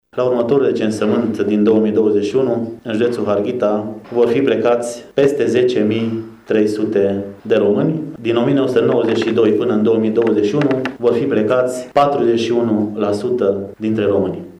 Prefectul judeţului Harghita, Andrei Jean-Adrian, a declarat azi, în cadrul lucrărilor Universităţii de Vară de la Izvorul Mureşului, că numărul românilor din judeţul Harghita se va reduce simţitor în următorii ani.
Prefectul judeţului Harghita, Andrei Jean-Adrian: